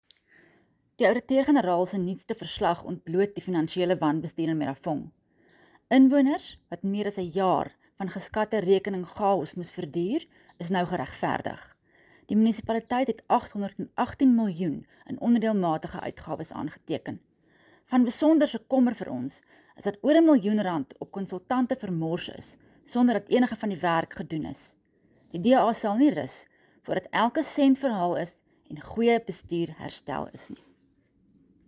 Afrikaans soundbites attached by Ina Cilliers MPL.